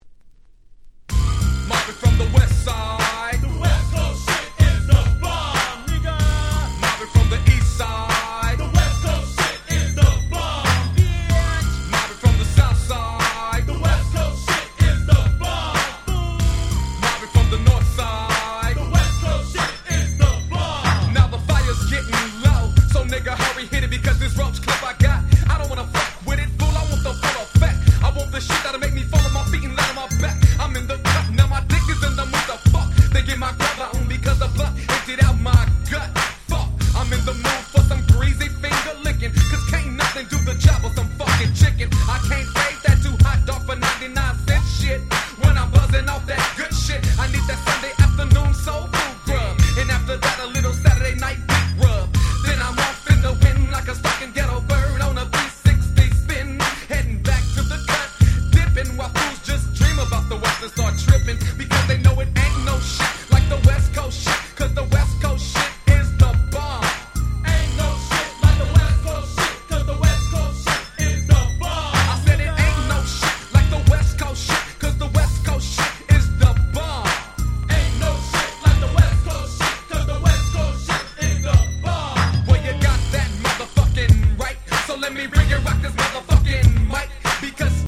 95' 人気G-Rap !!
Dopeな90's G-Rap Classicsです。
インシデンツ 90's プロモオンリー ウエストコースト ウエッサイ Gangsta Rap